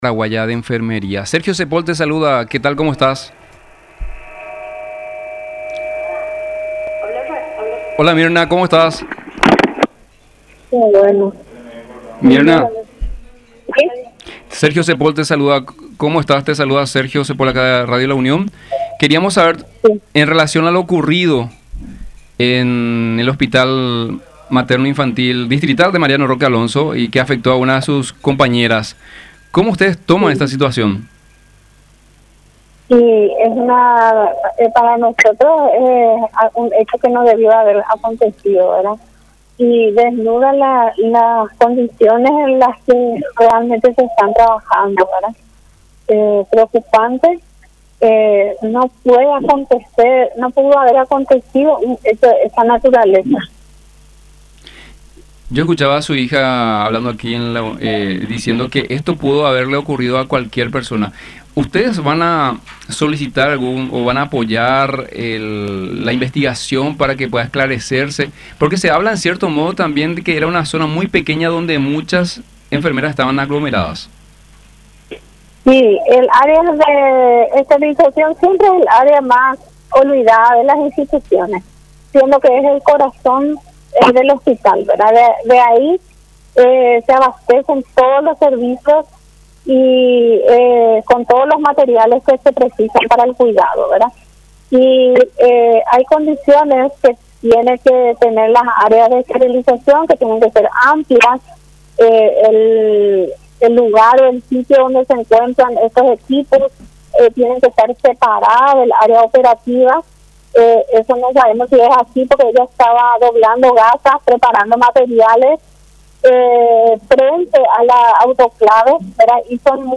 en conversación con Unión Informativa